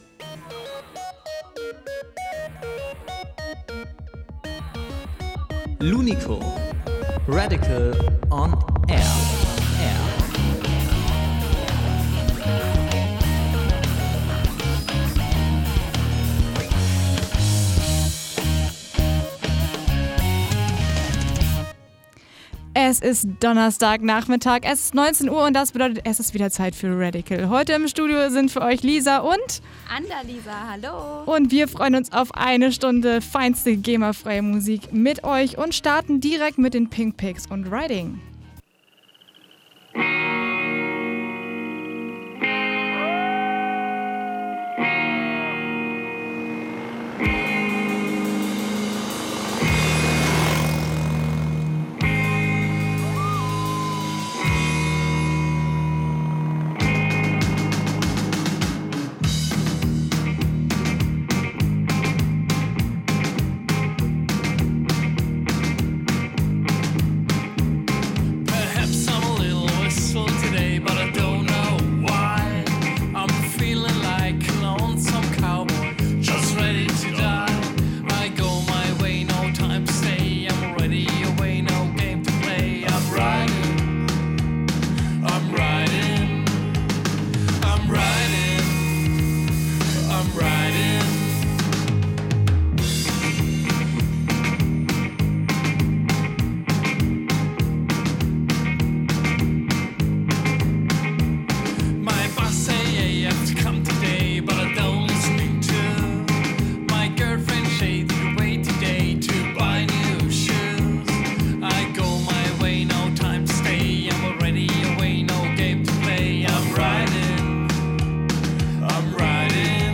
live im Studio THE ENTIRE PAST